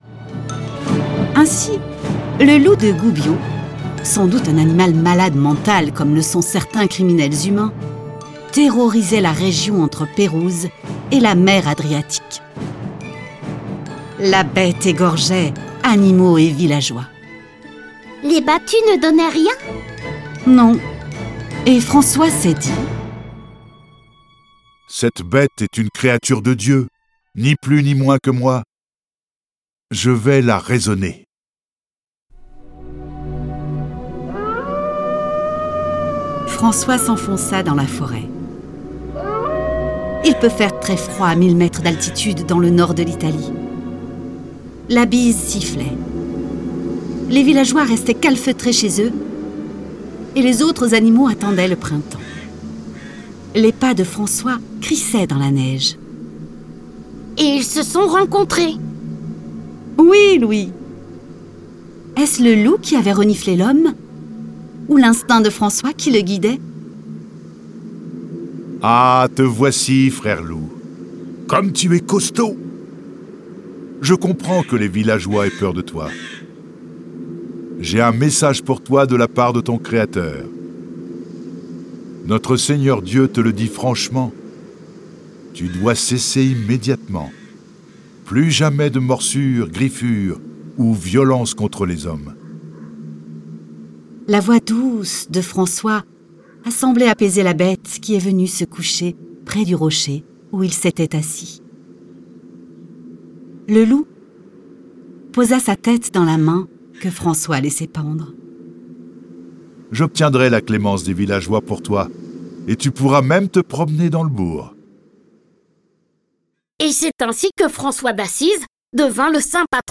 Diffusion distribution ebook et livre audio - Catalogue livres numériques
Il fonde l'ordre des Frères mineurs couramment appelé ordre franciscain. Cette version sonore de la vie de saint François est animée par 8 voix et accompagnée de quarante morceaux de musique classique.